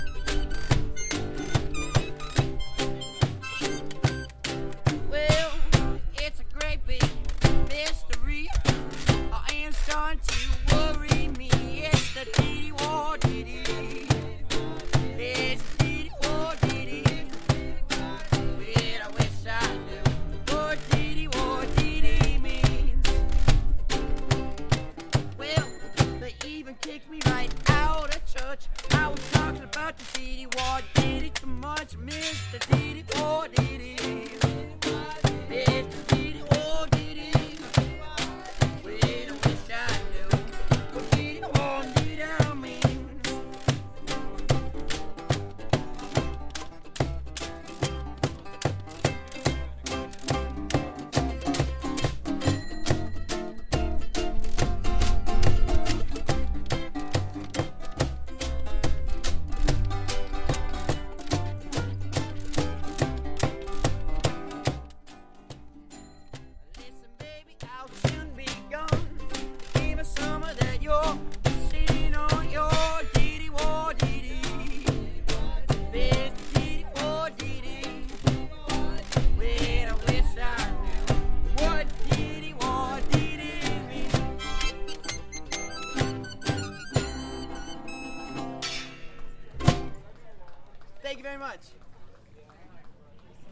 Finally, don’t leave the market without watching the buskers.
Busker-montage
BuskerMarketMP3.mp3